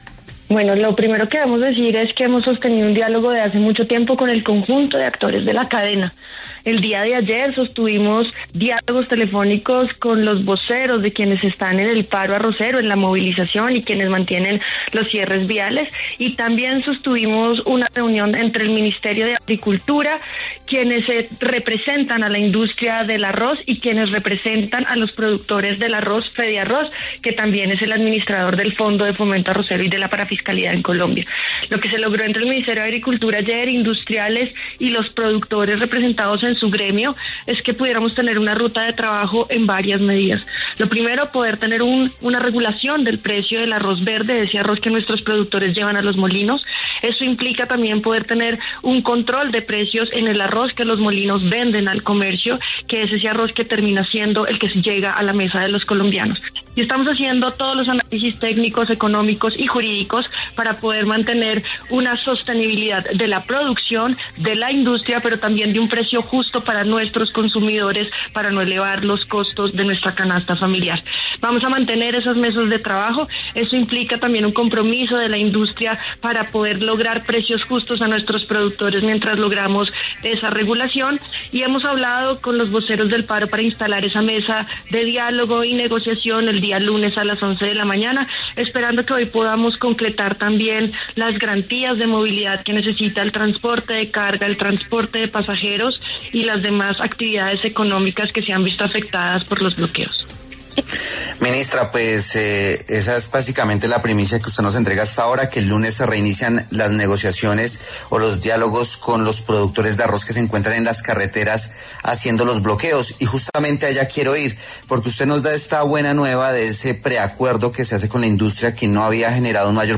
La ministra de Agricultura, Martha Carvajalino, pasó por los micrófonos de W Fin De Semana para hablar sobre la instalación de la mesa de negociación con los arroceros, los voceros de la industria del arroz y el Gobierno Nacional para buscar salidas al paro que bloquea vías principales del país.